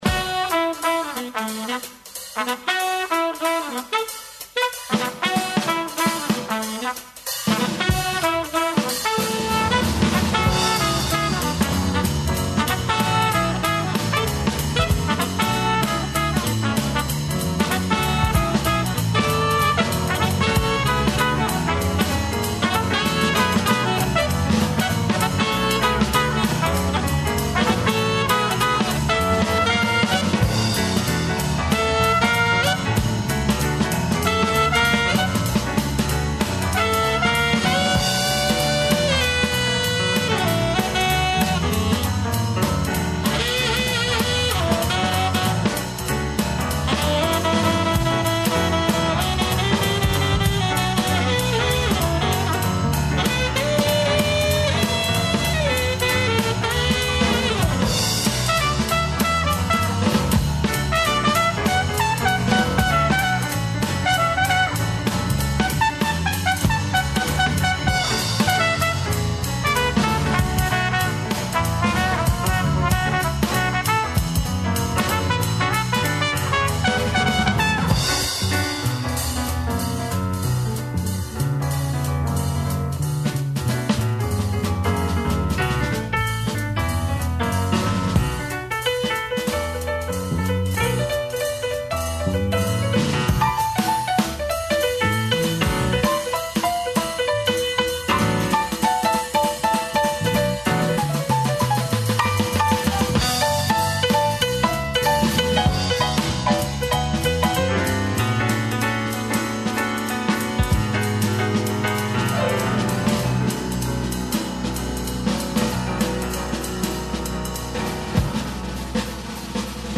који ће певати уживо, на нашем програму.